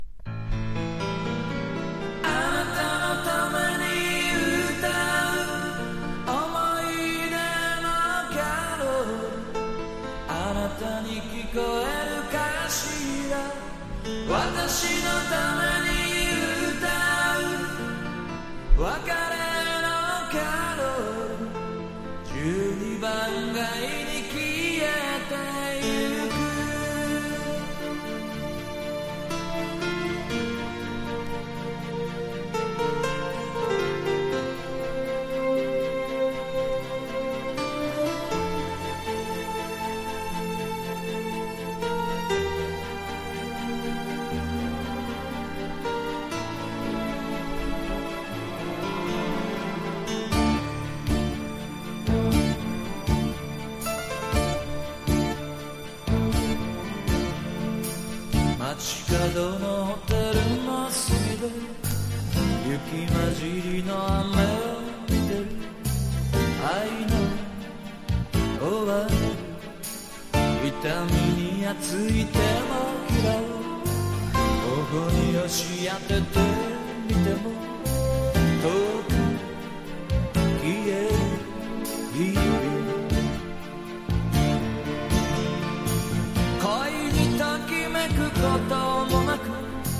SSW / FOLK# POP# 和モノ / ポピュラー